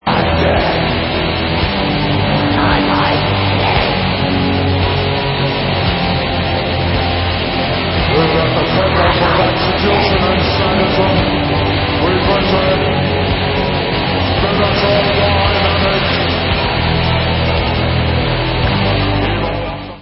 sledovat novinky v oddělení Rock - Speed/Thrash/Death Metal